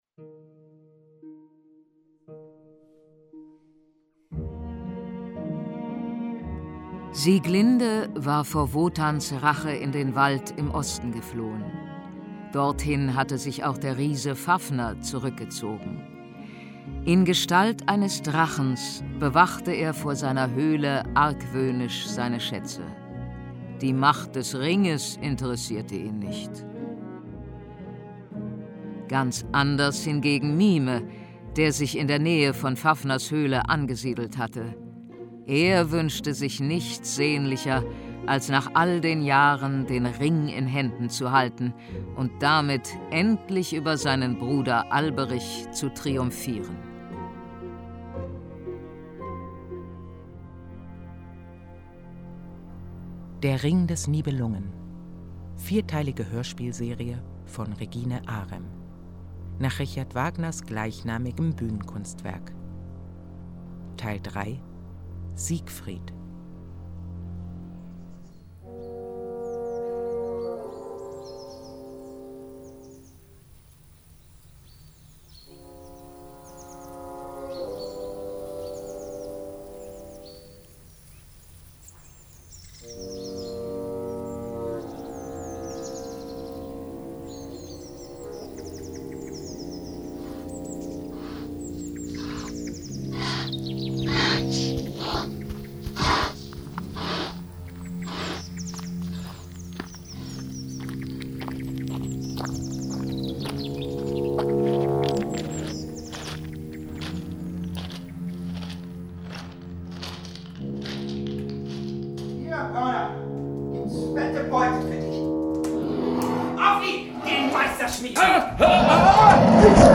Hörspiel: Siegfried.
Siegfried ein Hörspiel